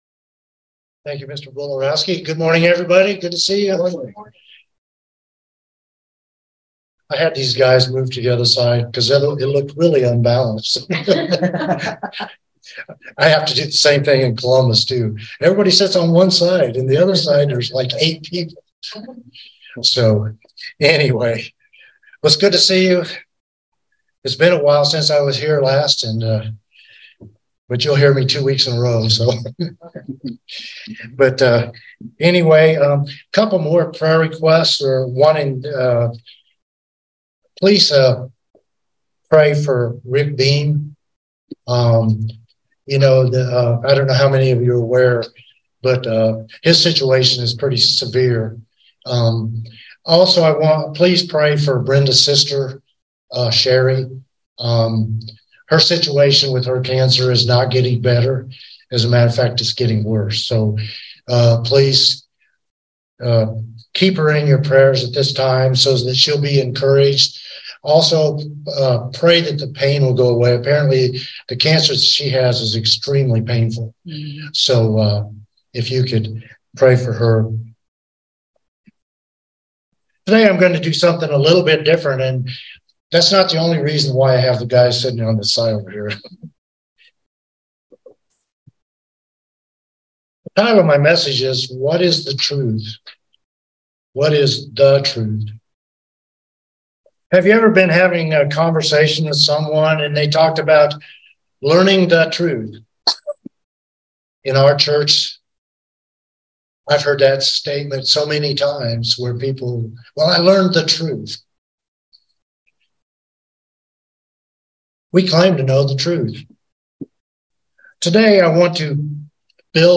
Given in Central Georgia Columbus, GA